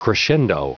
Prononciation du mot crescendo en anglais (fichier audio)
Prononciation du mot : crescendo